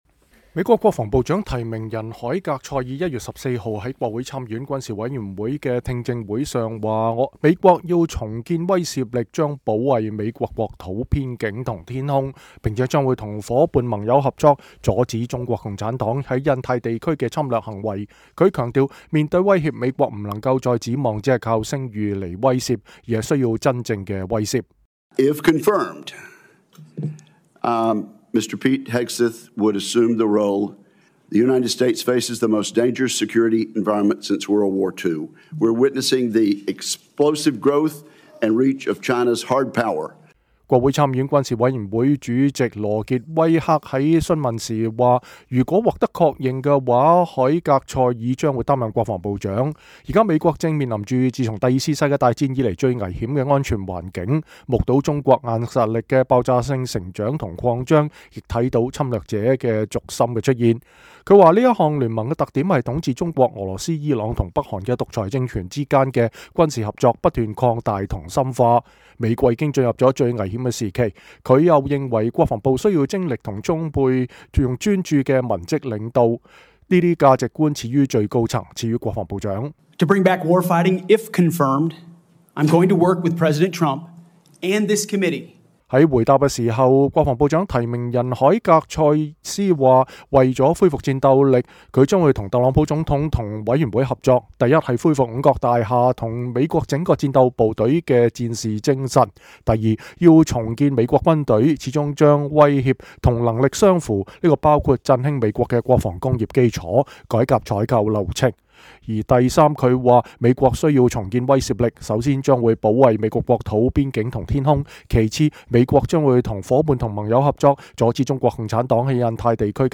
美國國防部長提名人海格塞斯1月14日在參議院軍事委員會的確認聽證會上說，「 我們要重建威懾力。我們將保衛我們的國土、邊境和天空。我們將與夥伴盟友合作，阻止中國共產黨在印太地區的侵略行為」 。他強調，面對威脅，美國不能再指望只靠聲譽來威懾，而是需要「真正的威懾」。